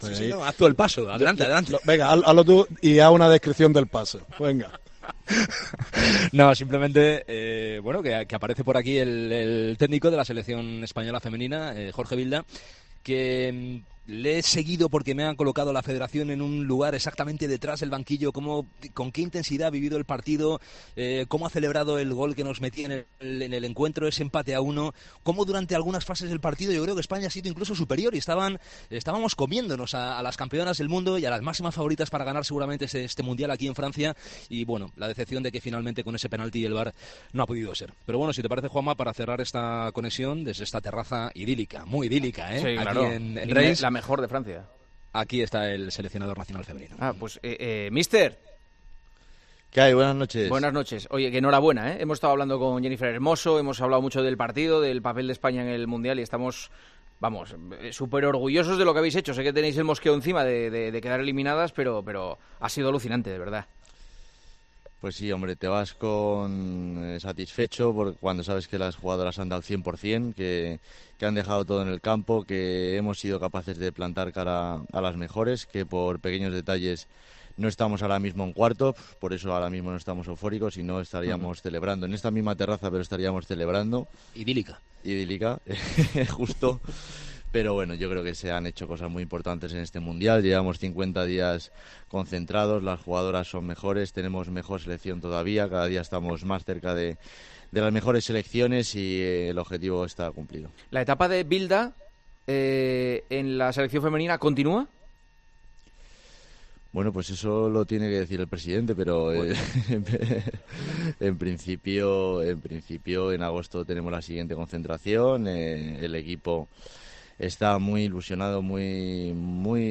La entrevista de Juanma Castaño al presidente de la RFEF, Luis Rubiales, en El Partidazo de COPE contaba este lunes con un testigo más que oportuno: el seleccionador del equipo femenino, Jorge Vilda.